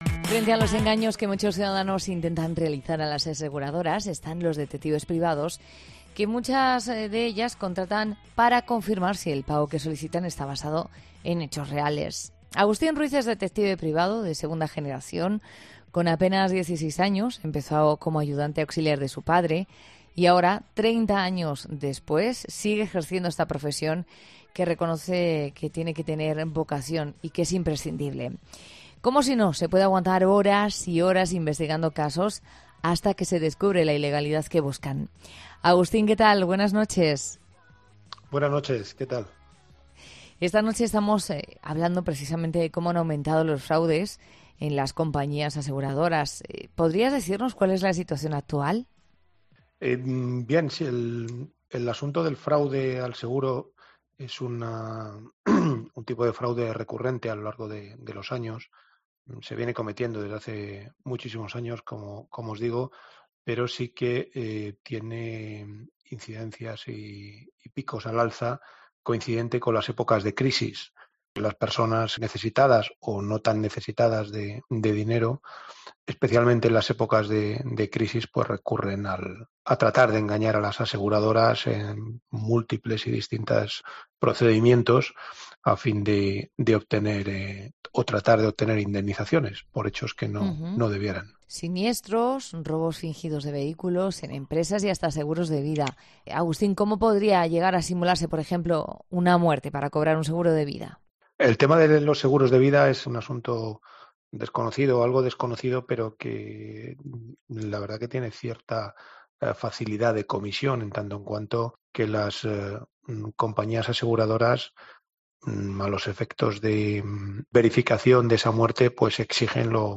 'La Noche de COPE' conoce con más profundidad esta forma de estafa en esta conversación